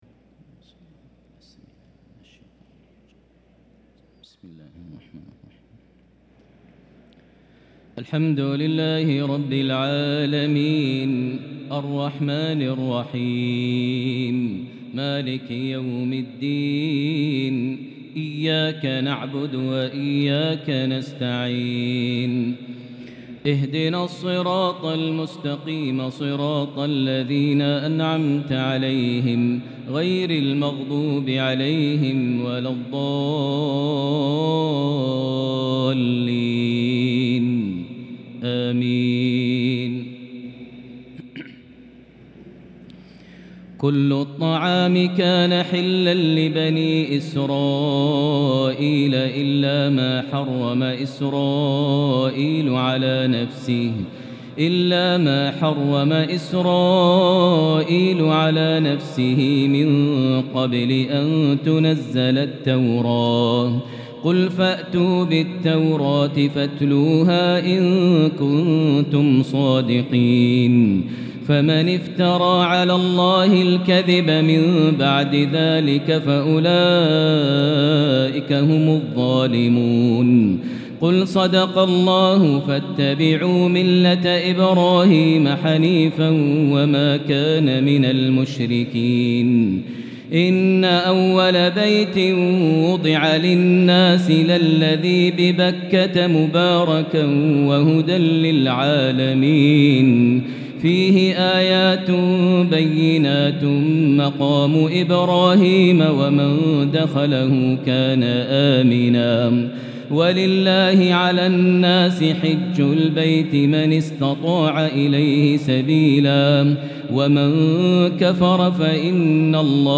تراويح ليلة 5 رمضان 1443هـ من سورة آل عمران (93-158) | Taraweeh 5st night Ramadan 1443H Surah Aal-i-Imraan 93-158 > تراويح الحرم المكي عام 1443 🕋 > التراويح - تلاوات الحرمين